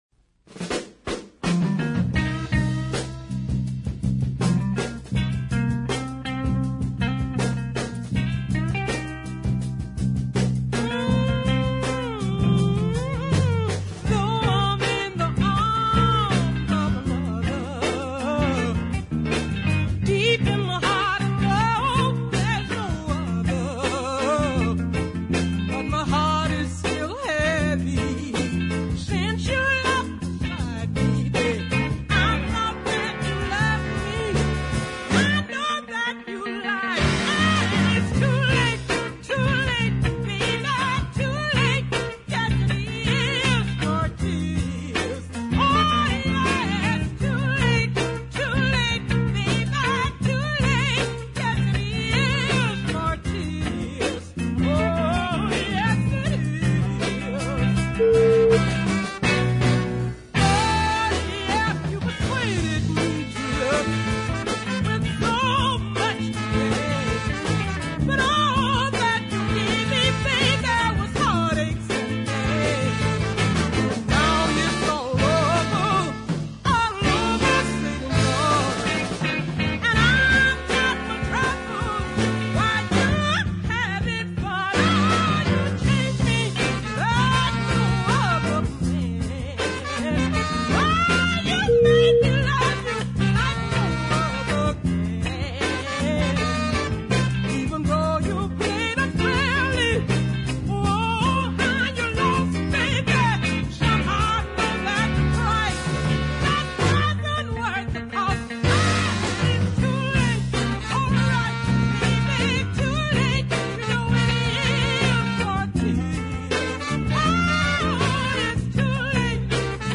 features some fine bluesy chord changes